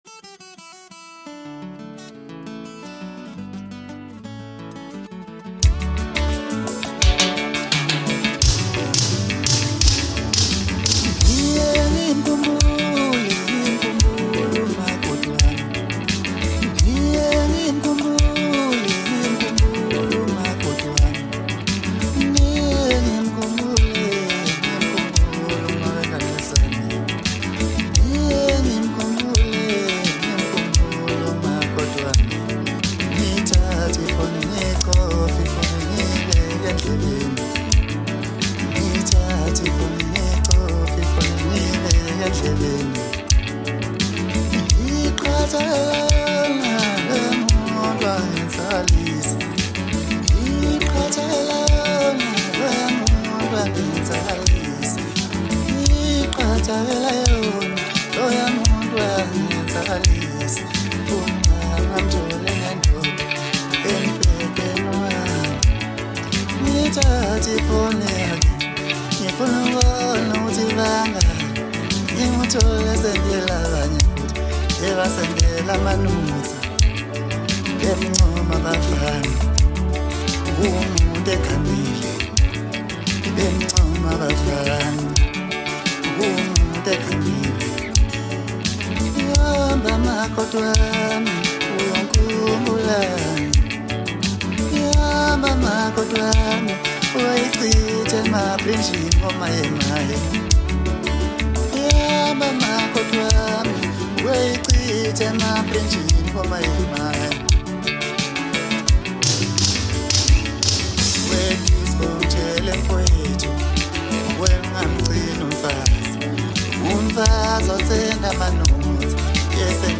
• Genre: Maskandi
South African singer-songwriter